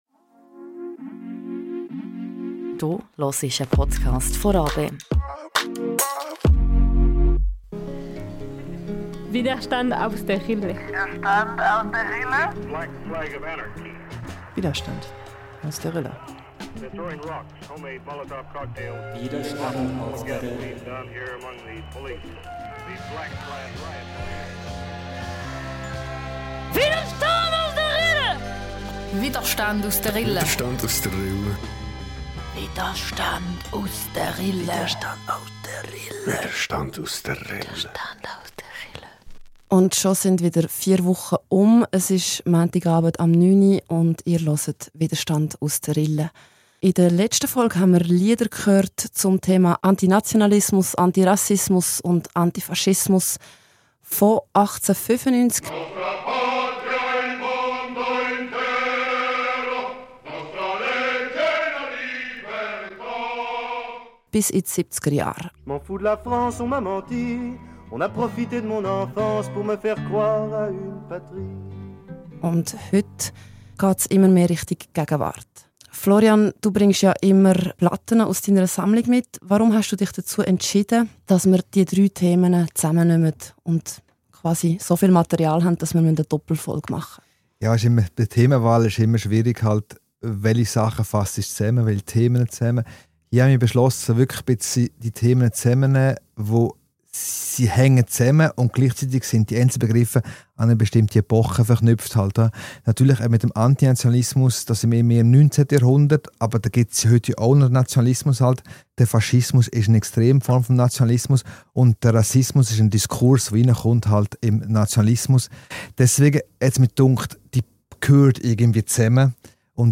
Der zweite Teil einer Doppelfolge mit Liedern zu den Themen Antirassismus, Antinationalismus und Antifaschismus.